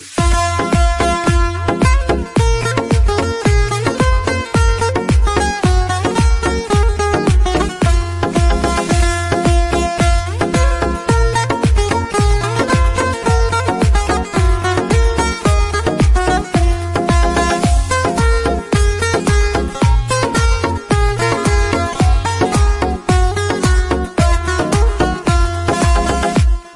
Genres: Indian (45)